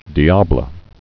(dēblə)